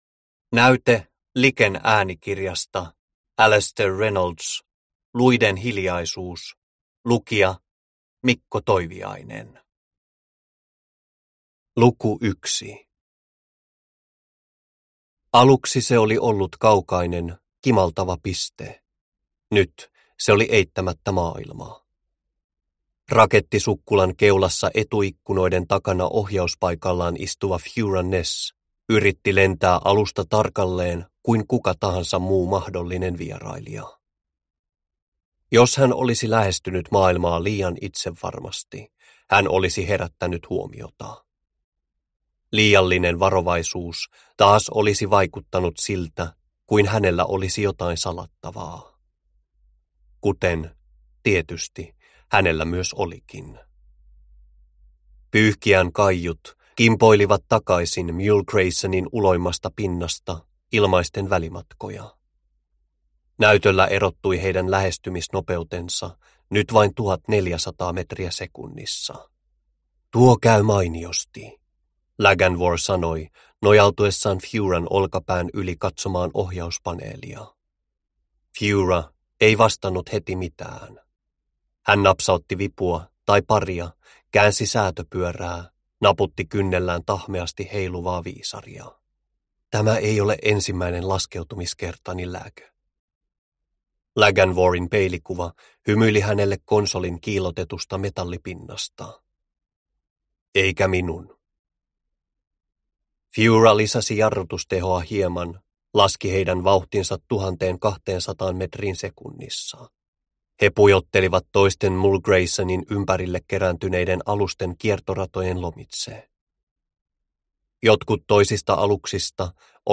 Luiden hiljaisuus – Ljudbok – Laddas ner